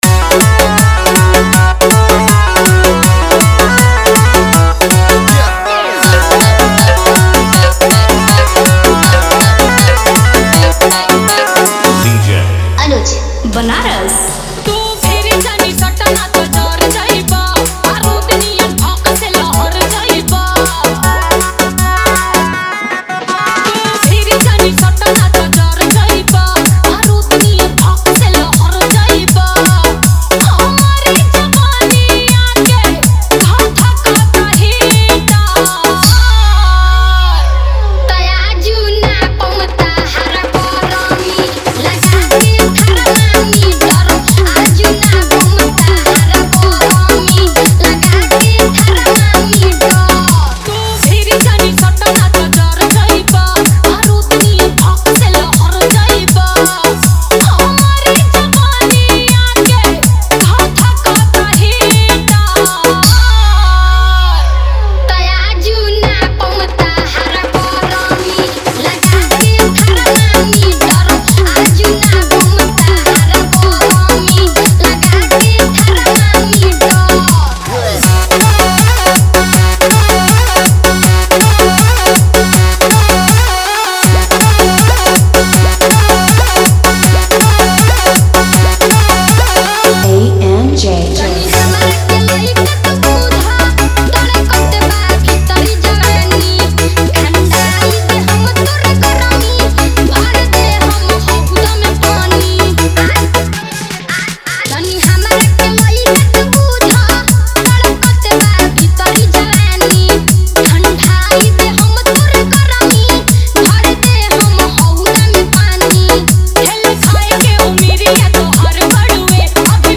आवाज़: सुपरहिट भोजपुरी कलाकार
वर्ग: डीजे मिक्स, डांस ट्रैक, देसी मस्ती